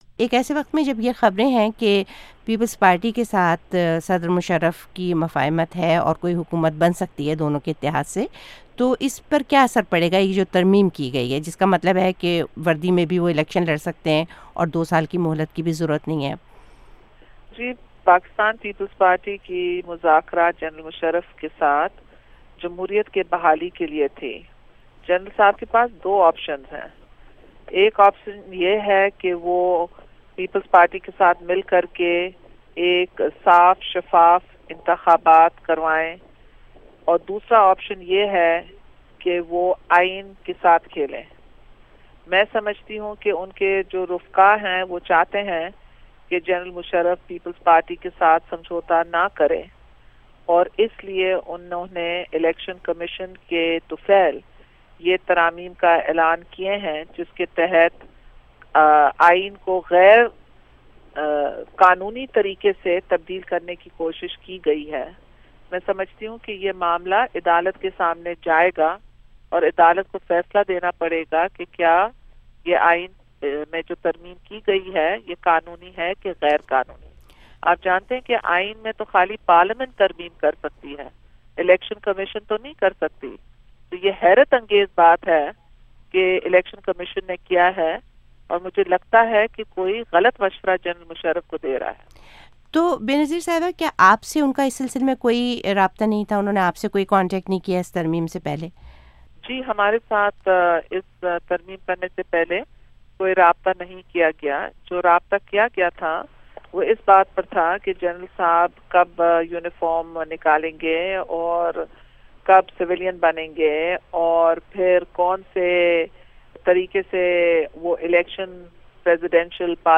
بے نظیر بھٹو کےساتھ انٹرویو